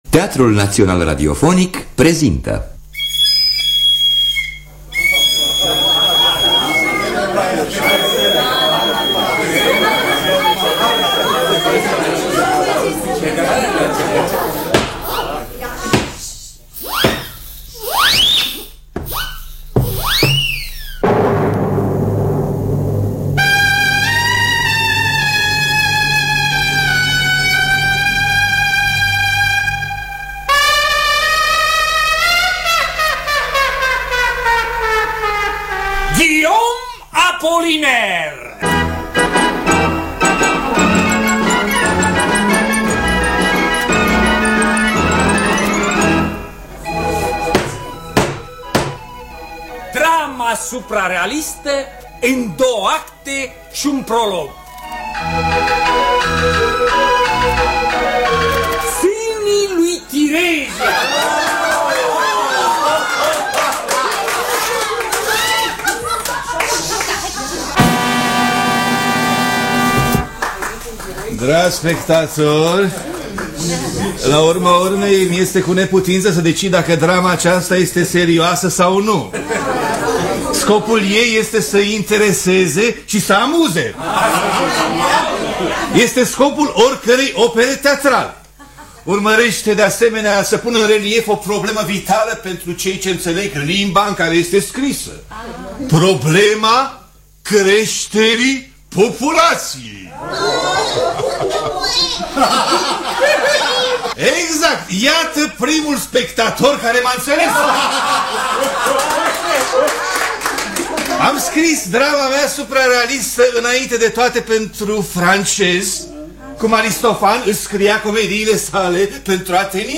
„Sânii lui Tiresias” de Guillaume Apollinaire – Teatru Radiofonic Online